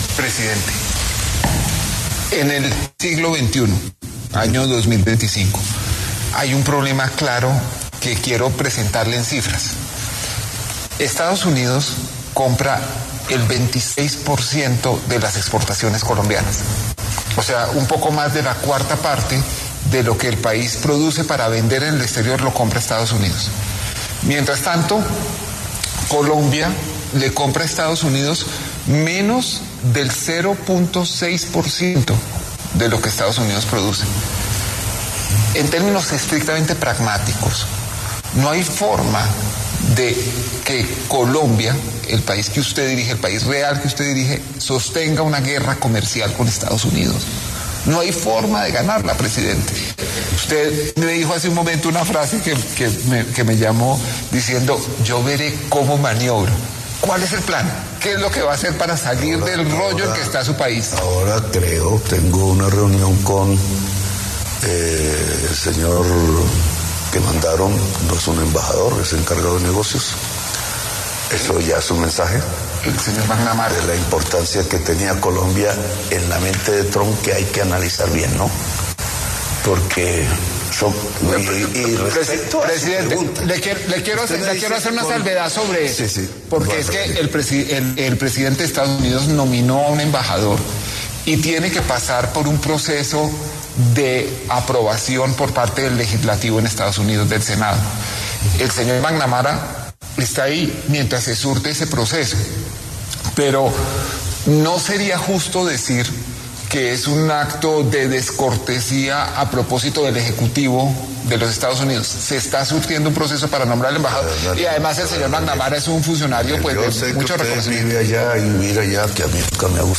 En diálogo con el periodista Daniel Coronell, el presidente Gustavo Petro aseguró que se reunirá con el encargado de Negocios de Estados Unidos, John McNamara, en medio de la nueva escalada de la crisis diplomática entre Estados Unidos y Colombia.